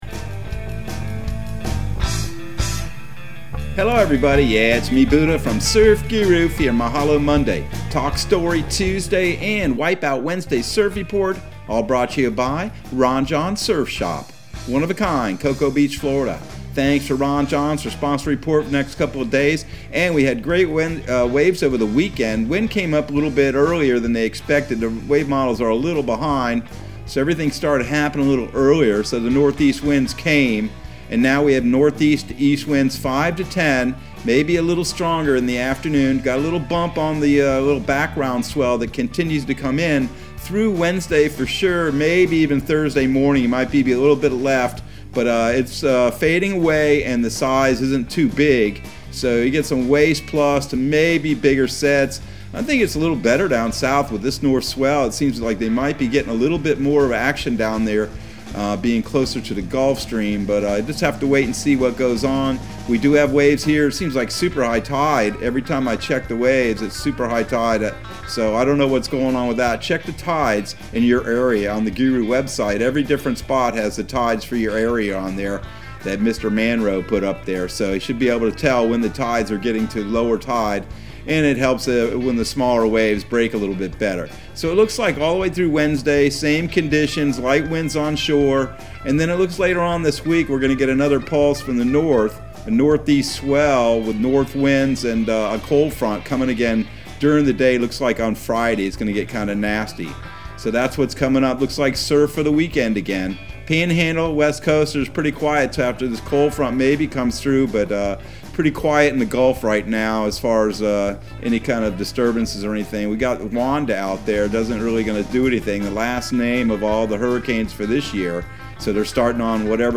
Surf Guru Surf Report and Forecast 11/01/2021 Audio surf report and surf forecast on November 01 for Central Florida and the Southeast.